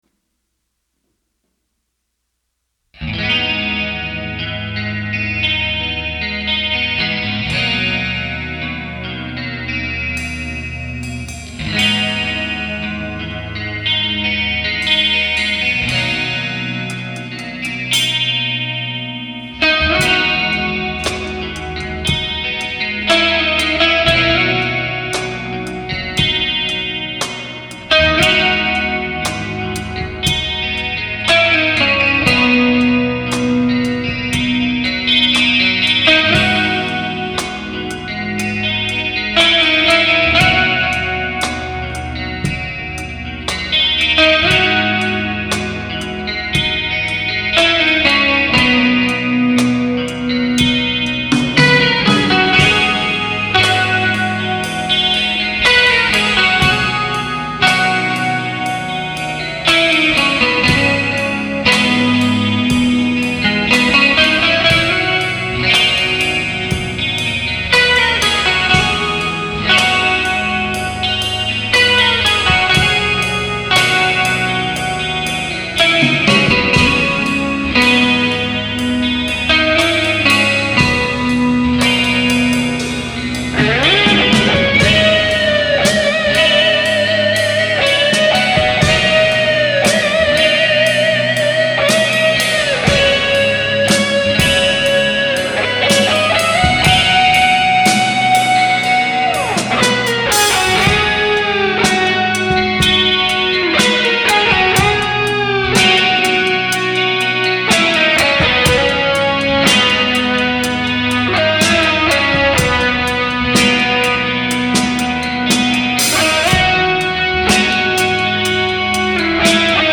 Guiter